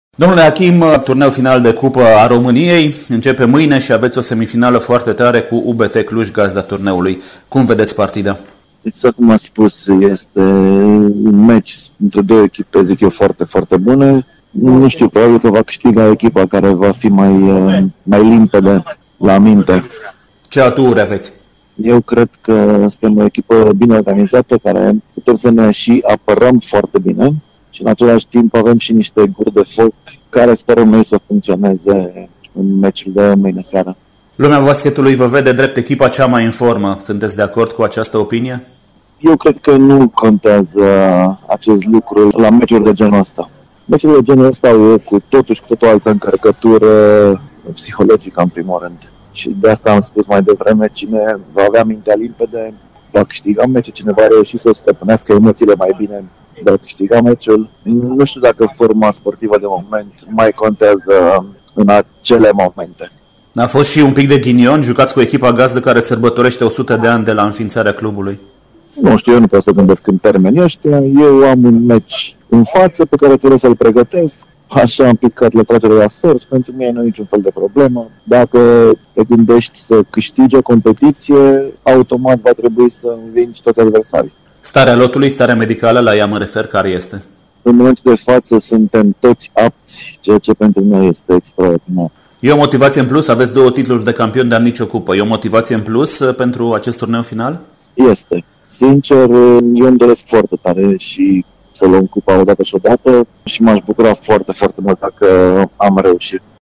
are întreg lotul la dispoziție și a vorbit pentru Radio Timișoara despre partida cu clujenii.